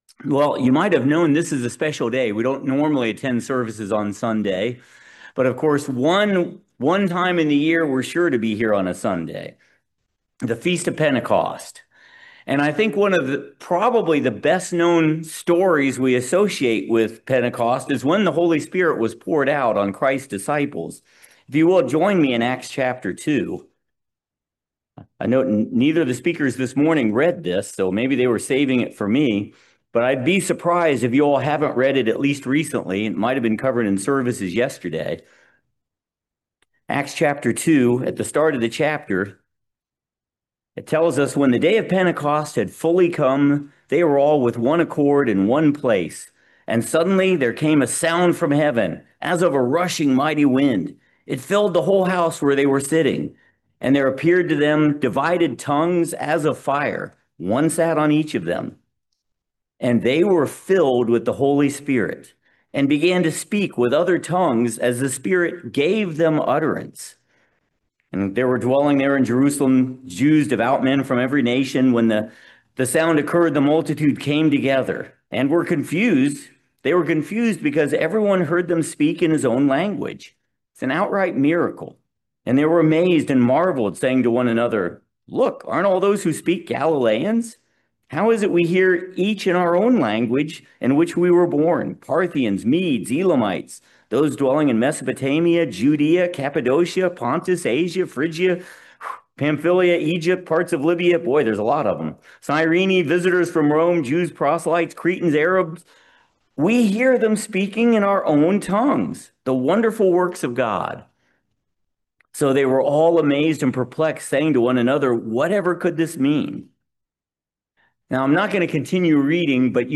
Join us for this excellent video sermon about the Holy Day of Pentecost. Do we understand the gifts of the spirit? Do we understand the fruits of the spirit?